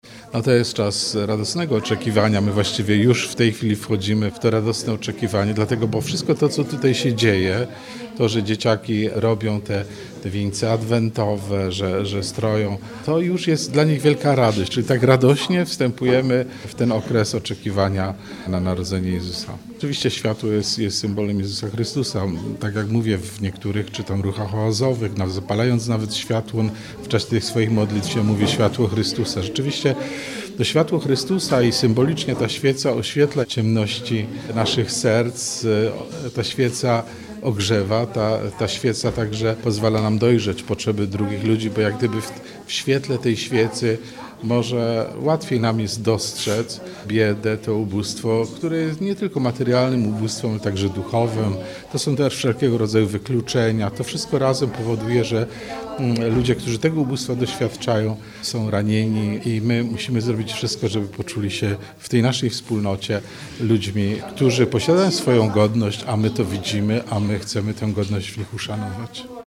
– Jesteśmy tu po to, aby rozpocząć wspaniałą akcję pomocy dzieciom. Ta świeca symbolizuje światło, pomaga zauważyć potrzeby innych – mówi abp Józef Kupny, metropolita wrocławski.